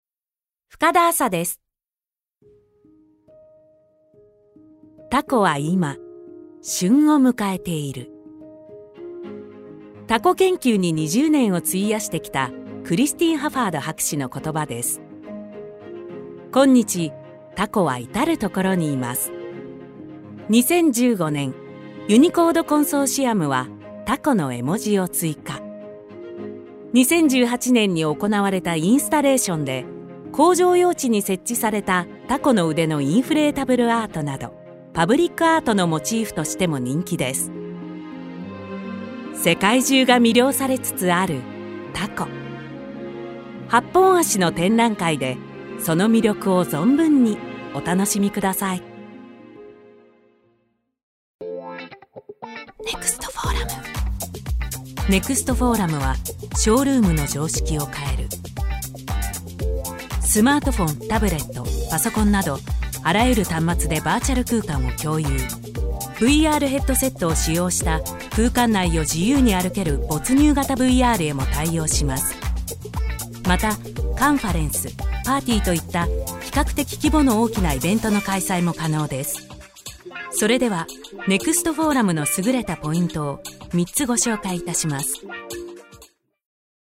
ボイスサンプル
• 音域：中音〜低音
• 声の特徴：クール、ナチュラル、さわやか